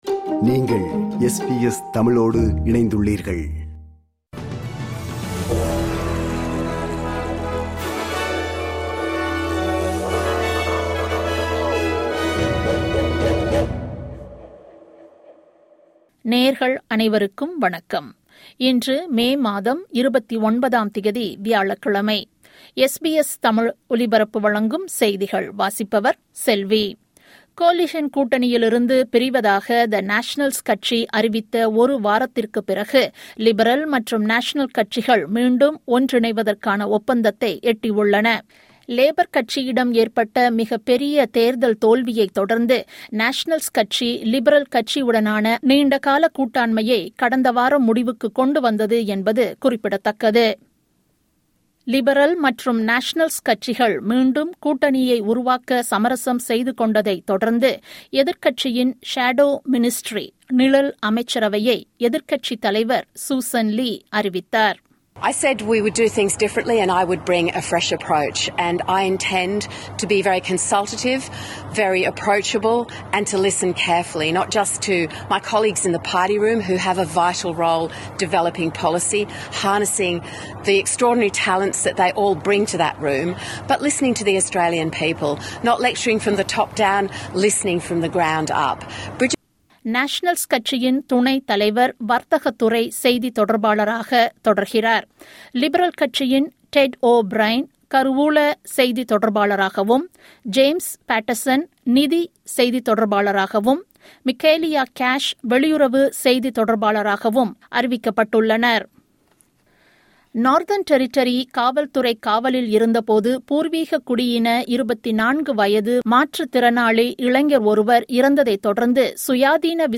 SBS தமிழ் ஒலிபரப்பின் இன்றைய (வியாழக்கிழமை 29/05/2025) செய்திகள்.